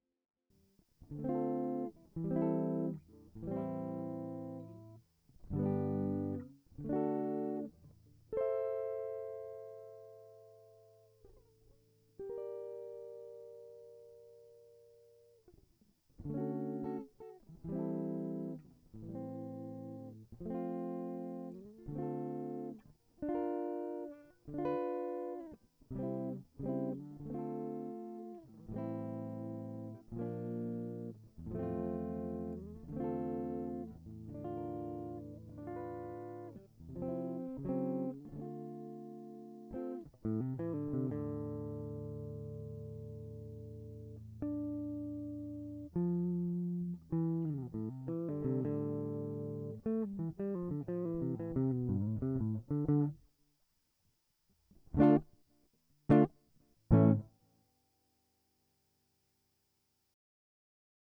clean signal for reference